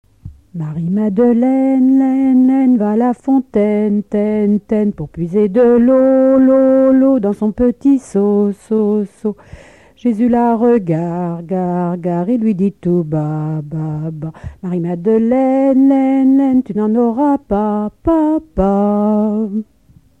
Saint-Florent-des-Bois
Enfantines - rondes et jeux
Pièce musicale inédite